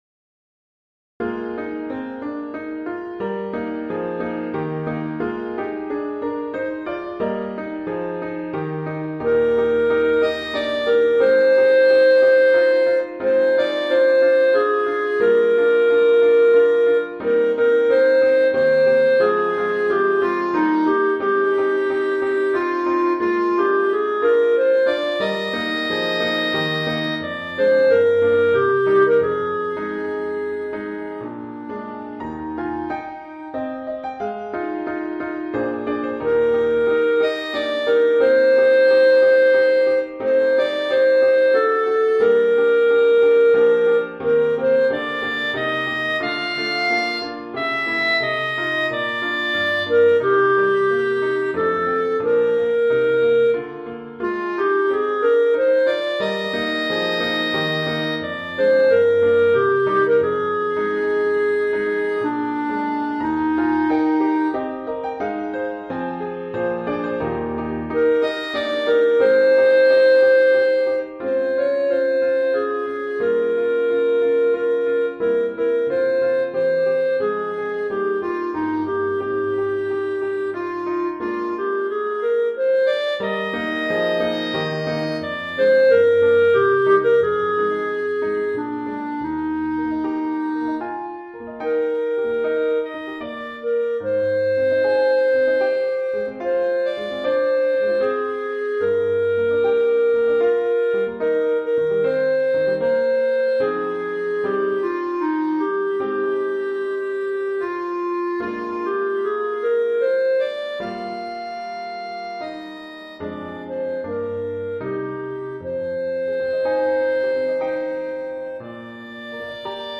avec transcription pour clarinette de la partie vocale (DR.)